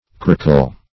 Search Result for " corcle" : The Collaborative International Dictionary of English v.0.48: Corcle \Cor"cle\ (k[^o]r"k'l), Corcule \Cor"cule\ (-k[-u]l), n. [L. corculum a little heart, dim. of cor heart.]
corcle.mp3